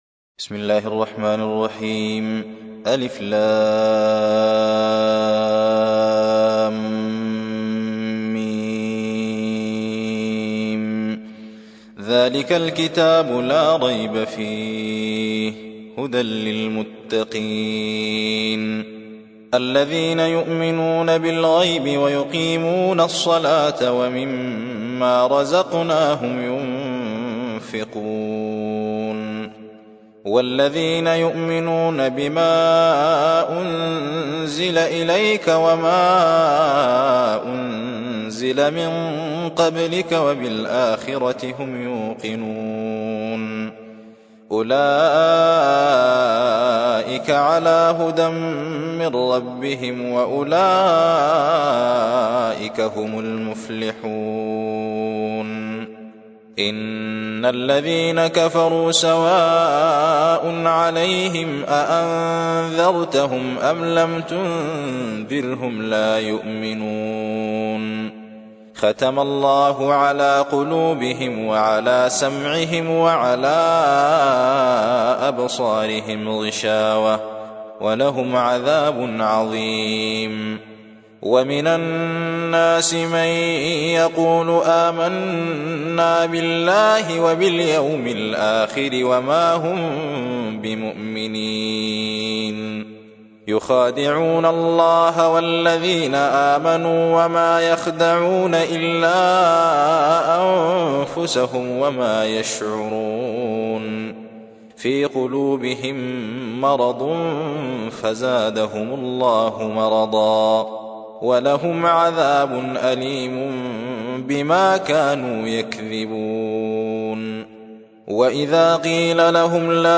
2. سورة البقرة / القارئ
القرآن الكريم